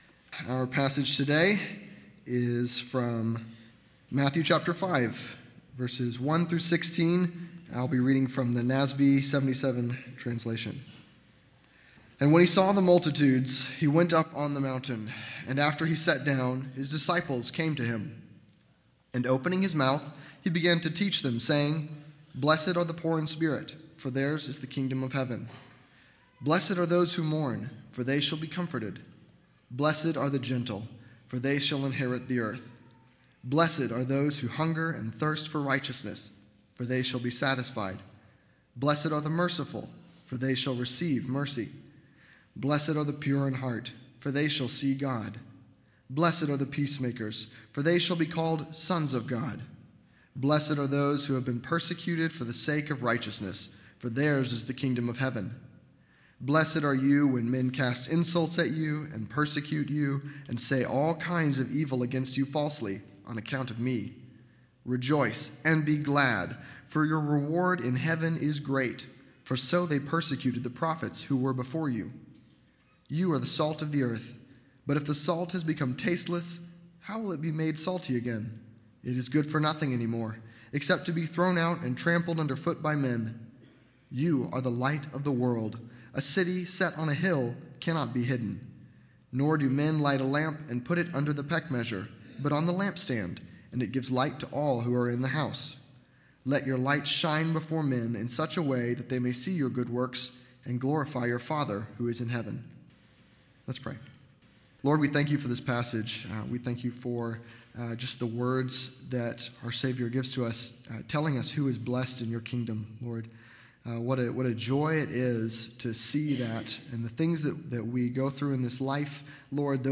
Sermons - Community Bible Chapel, Richardson, Texas